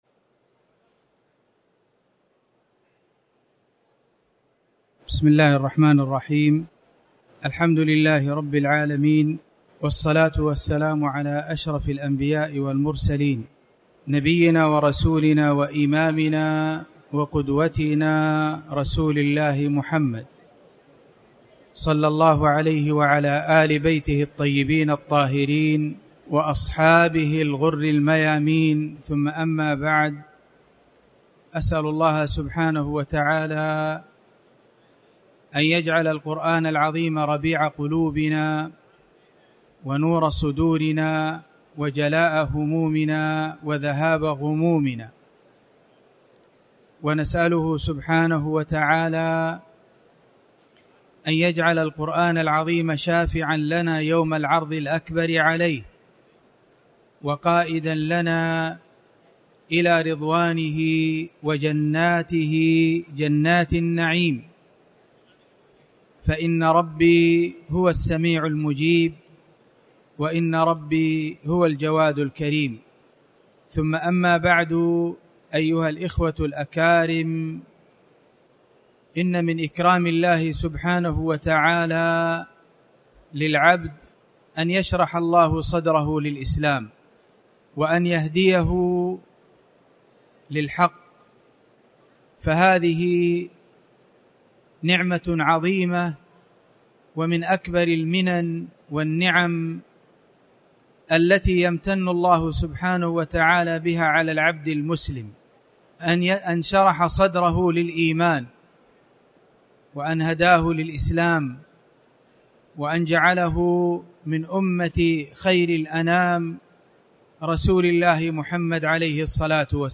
تاريخ النشر ٢٤ ذو القعدة ١٤٣٩ هـ المكان: المسجد الحرام الشيخ